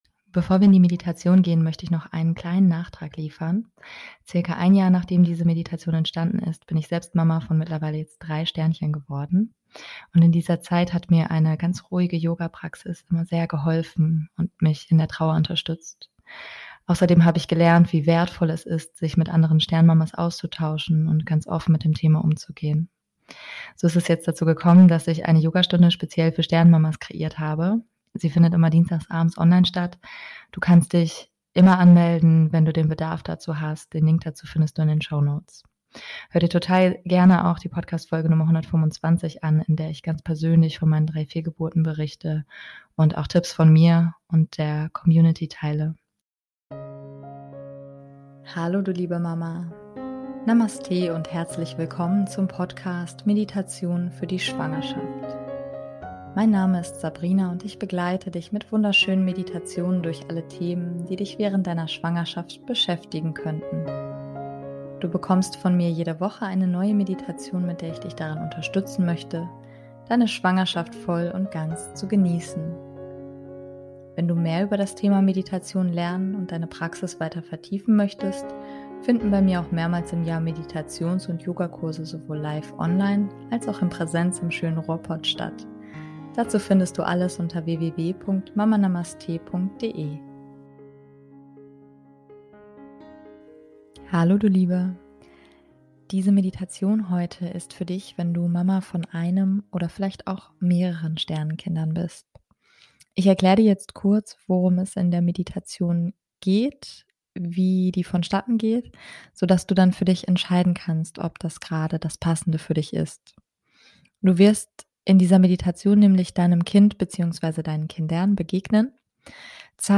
#077 - Sternenkind Meditation ~ Meditationen für die Schwangerschaft und Geburt - mama.namaste Podcast